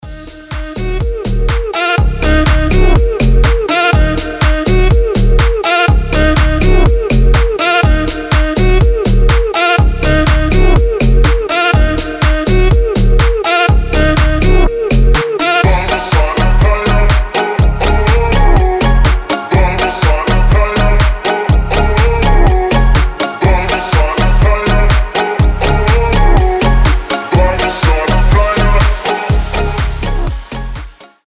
• Качество: 320, Stereo
позитивные
ритмичные
заводные
house